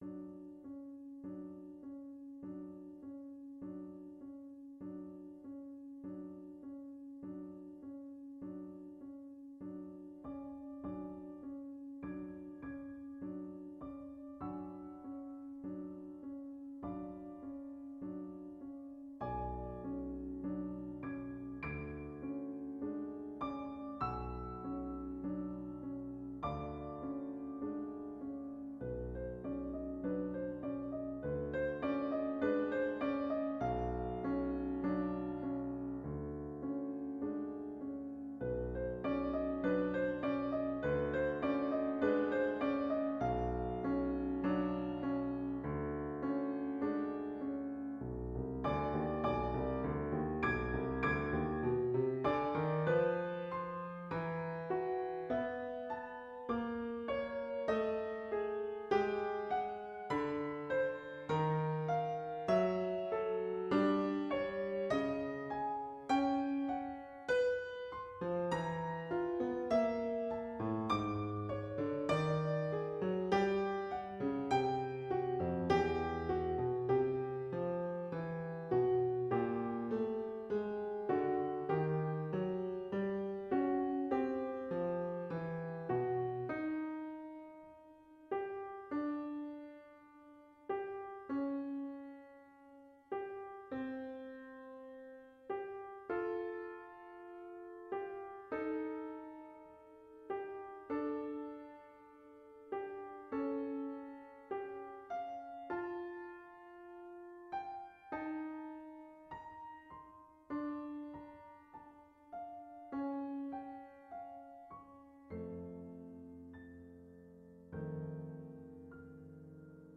Psalm Offering 5 Opus 2 for piano
This is not a pleasant, tune-filled Psalm Offering. It begins with diminished chords and leads to a section of 12 tone music. It gradually moves from dissonance to a quiet resolution of consonance.
Dissonance to consonance.